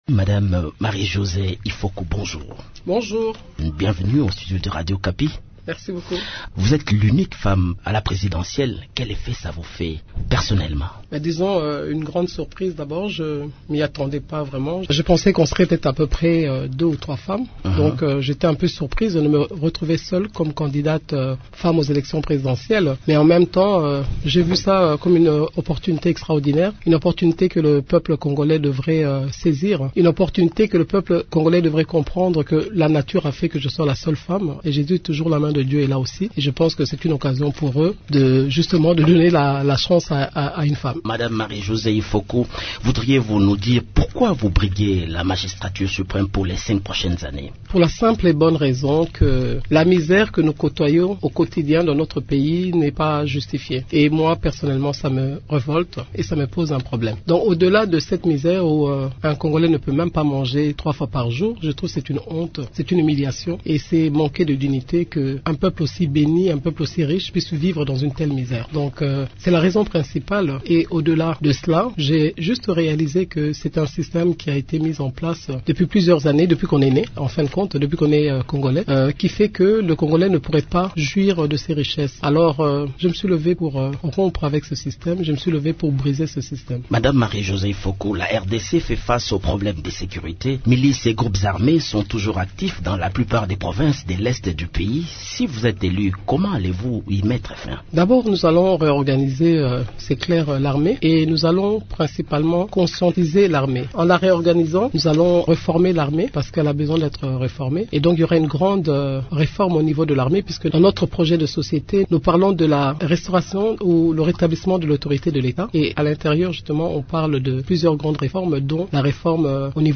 Marie Josée Ifoku Mputa Mpunga décline son programme de société dans cette interview